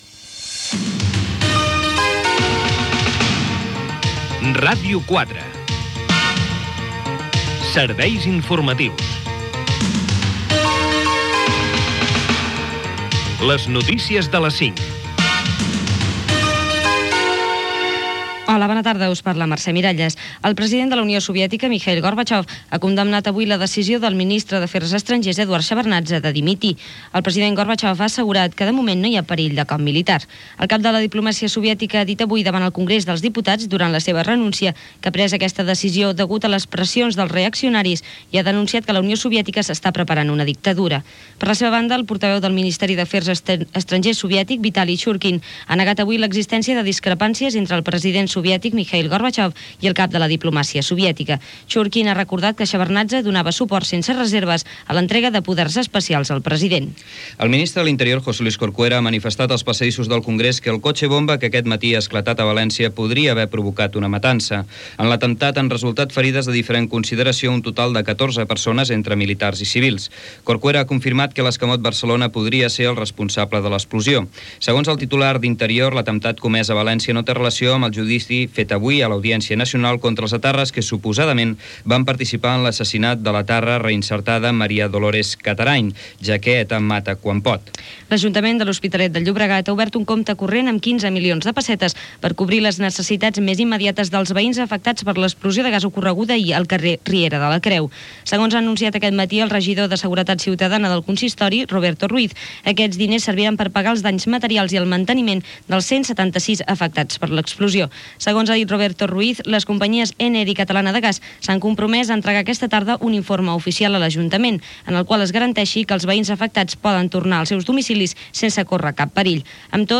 Careta del programa,declaracions de Mikhaïl Gorbatxov, explossió d'un cotxe bomba a Valéncia, explossió de gas a L'Hospitalet de Llobregat, indicatiu, expropiacions per fer el segon cinturó i el cinturó del litoral, debat de pressupostos al Parlament de Catalunya, esports
Informatiu